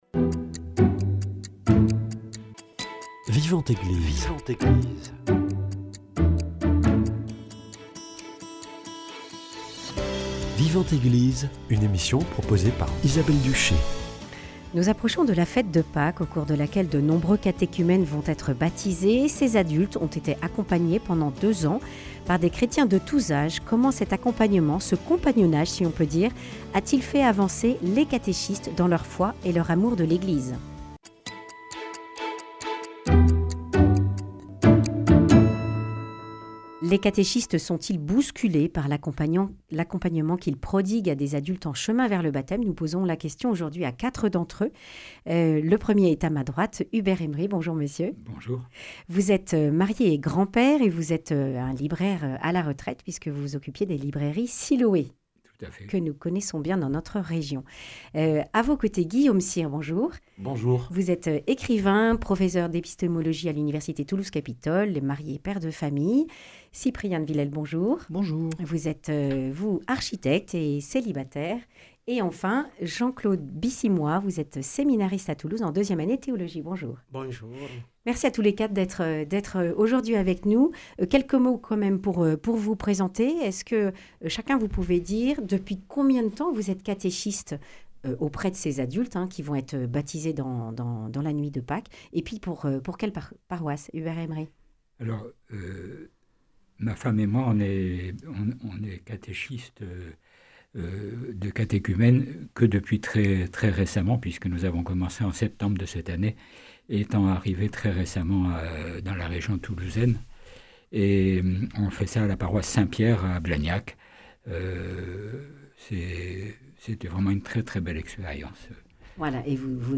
Des paroisses de Blagnac et de la cathédrale de Toulouse, quatre catéchistes témoignent de la façon dont ils accompagnent les catéchumènes. Un accompagnement qui questionne et fait grandir dans la Foi.